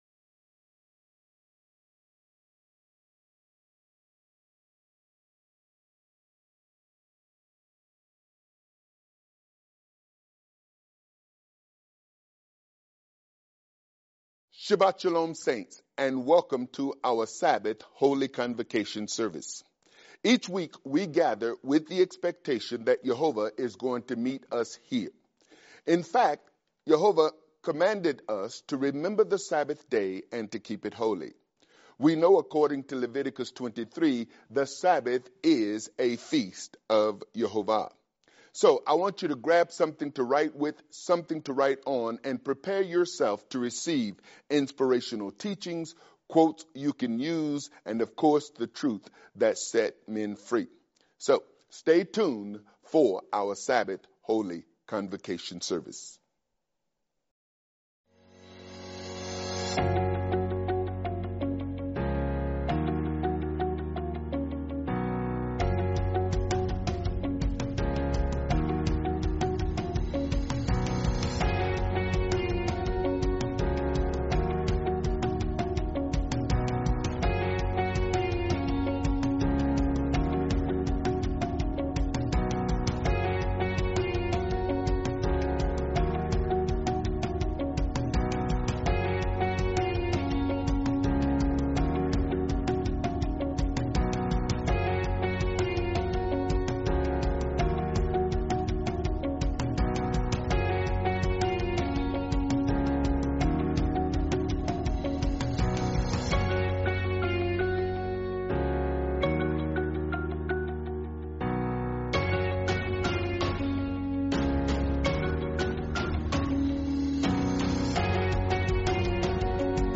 Prayers That Get Results | Teaching